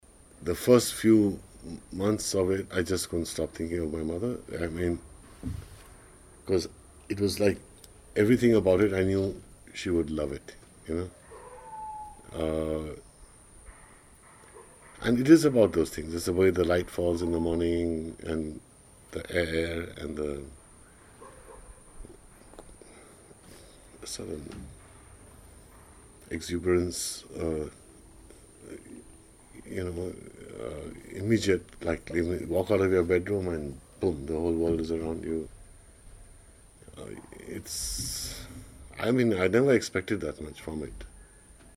Excerpts from a conversation